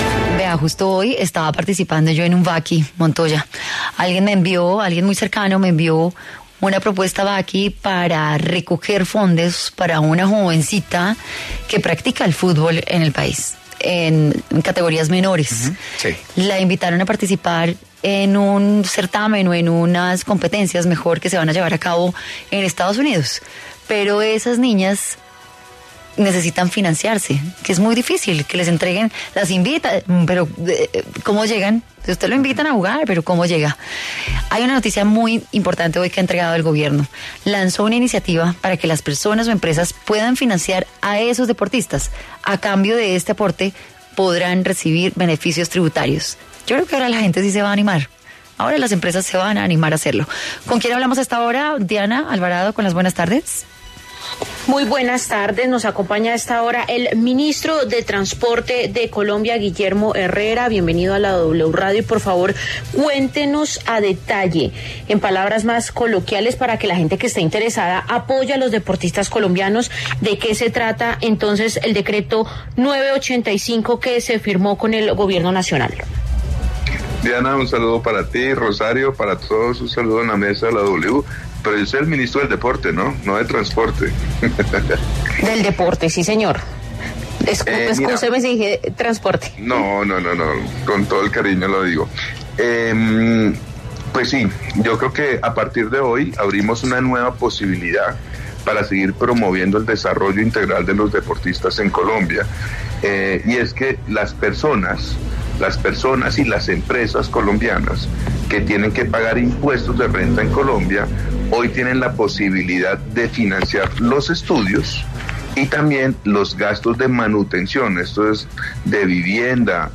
El ministro de Deporte Guillermo Herrera, explicó para Contrarreloj de W Radio, cómo será la contribución a quién decida patrocinar a un deportista.